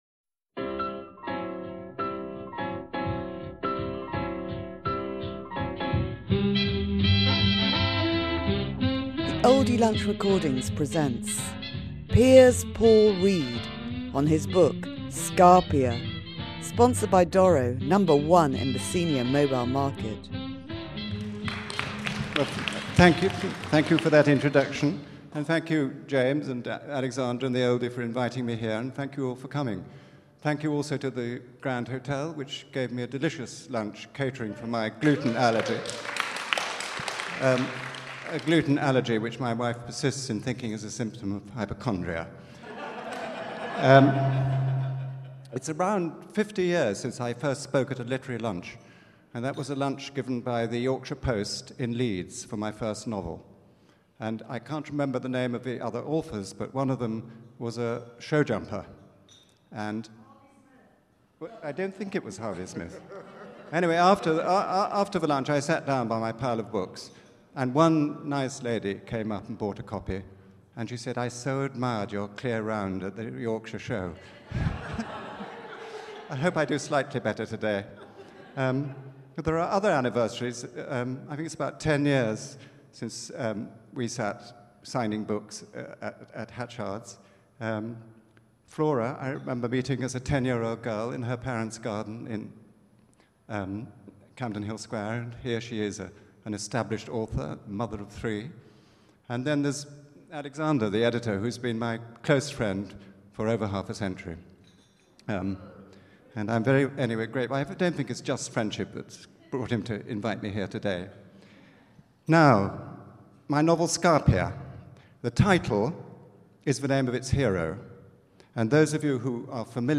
Oldie Literary Lunch: Piers Paul Read, Scarpia